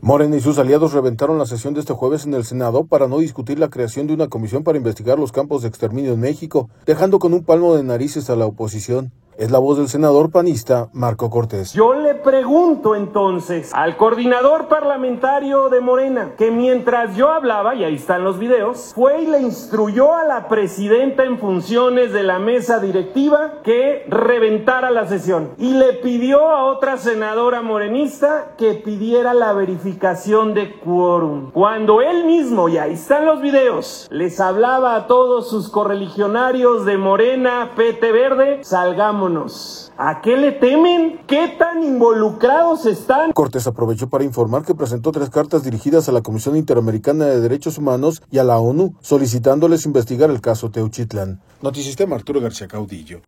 Morena y sus aliados reventaron la sesión de este jueves en el Senado para no discutir la creación de una Comisión para Investigar los Campos de Exterminio en México, dejando con un palmo de narices a la oposición. Es la voz del senador panista Marko Cortés.